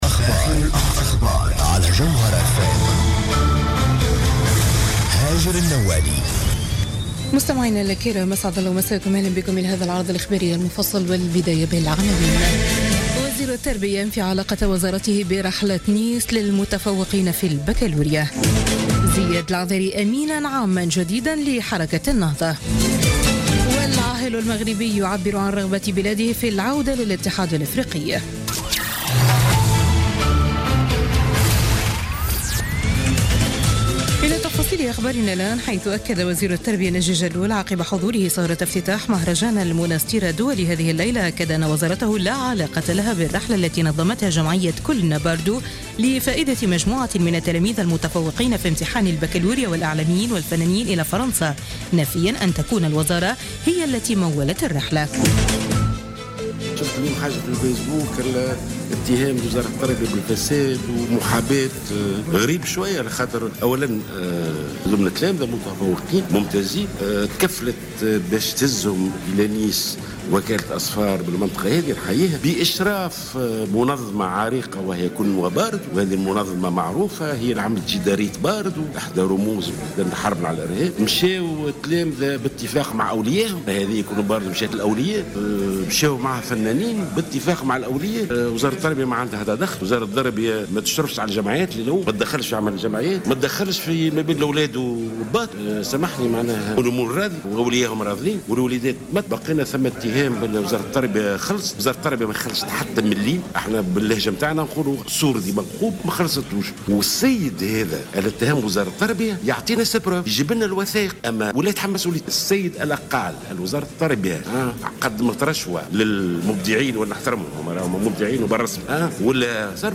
نشرة أخبار منتصف الليل ليوم الإثنين 18 جويلية 2016